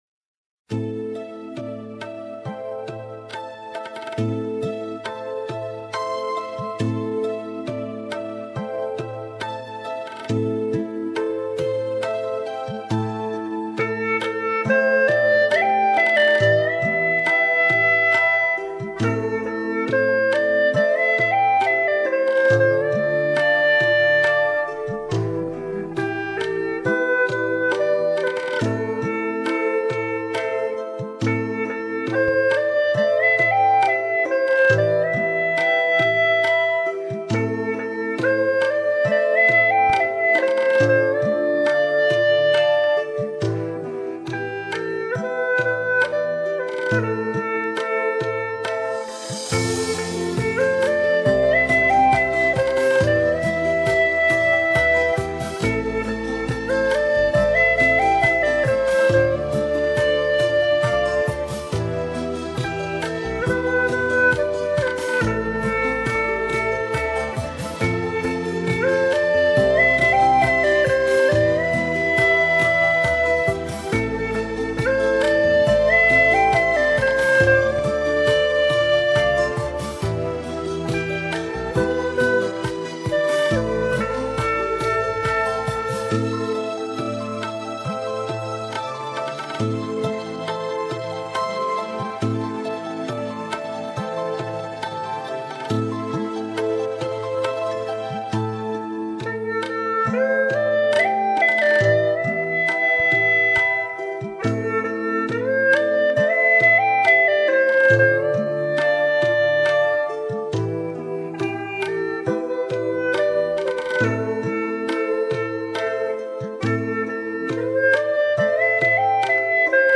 试录
进步好大，好像腹颤也有了，滑音不少也到位，节奏准那是强项，略平些了，献花！
呵呵呵  天神啊，这速度都快赶上美国打火星了，曲子节奏非常稳。个人觉得吹得很紧，不够放松，腹震有了啊，呵呵呵